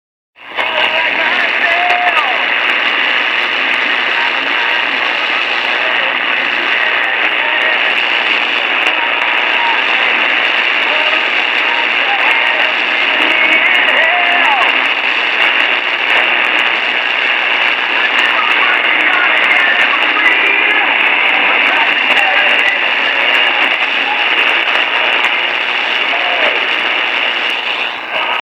Sheridan Shortwave Logs + Other Interesting Logs on Non BCB Frequencies
Radios used are Grundig S350 and Grundig G8 Traveller II.
WBCQ (QSL'd) 7490 Monticello, ME 2-6-15 Rock mix program (Fred Flinstone Music Prgm)